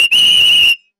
Referee Whistle
A sharp referee whistle blast cutting through noise with authoritative pea rattle
referee-whistle.mp3